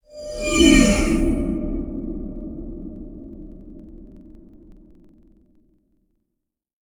WarpCoreOpen.wav